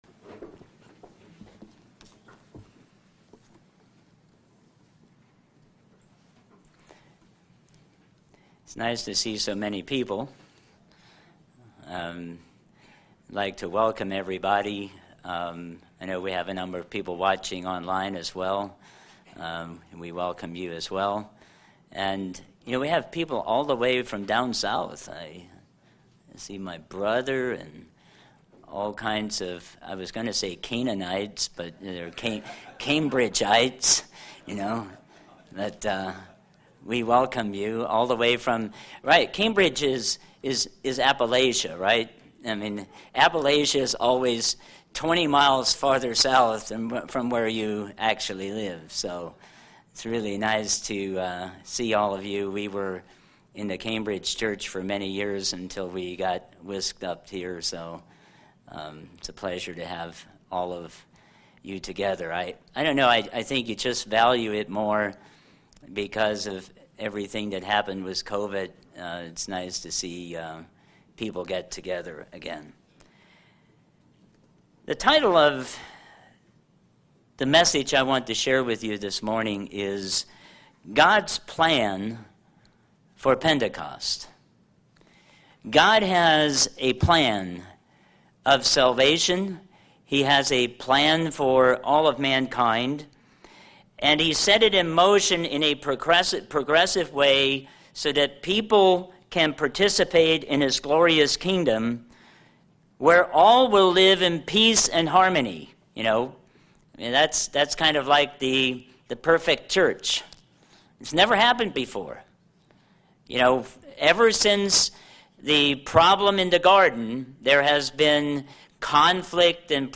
What are the futuristic events the day of Pentecost depicts? This sermon uses scripture to provide a thorough recap of the impending events of this holy day.